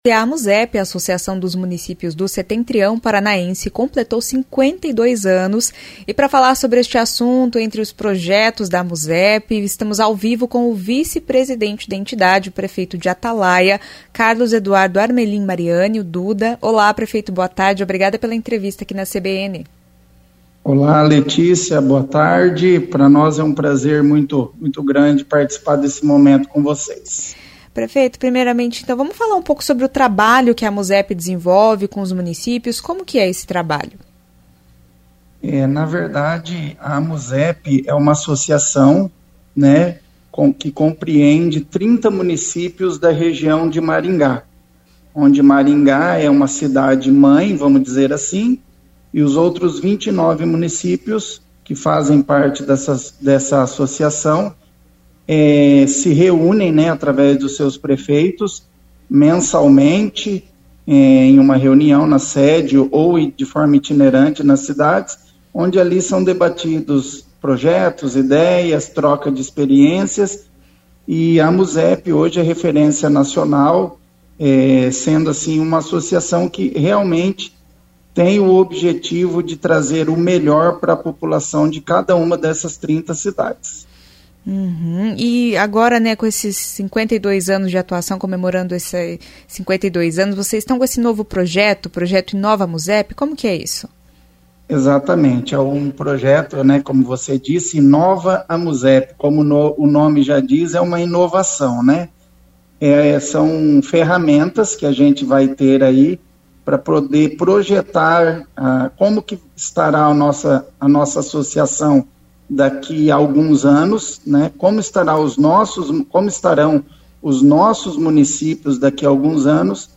Ouça a entrevista com o o vice-presidente da Amusep, prefeito de Atalaia, Carlos Eduardo Armelin Mariani.